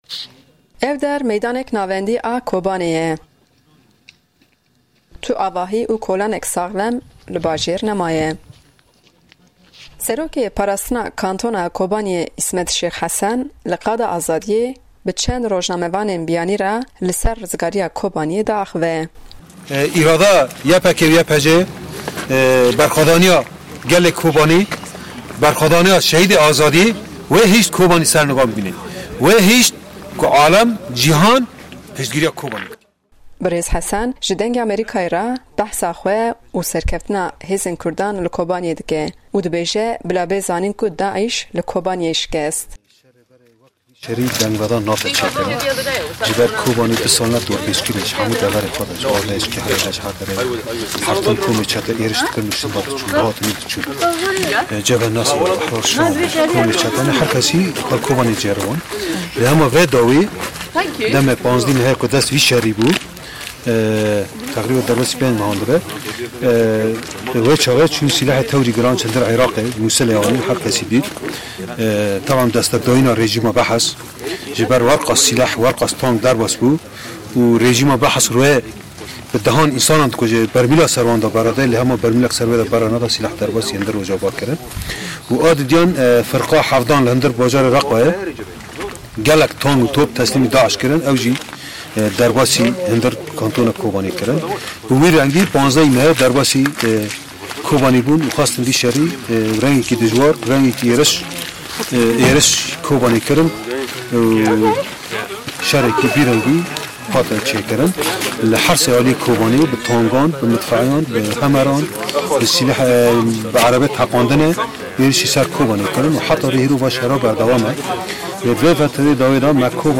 Raporteke Taybet li Ser Pêşangeha Kurdistan li Amedê